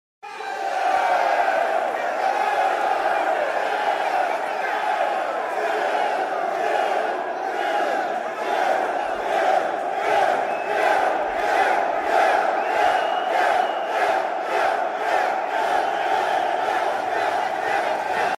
Звук бунта заключённых в тюрьме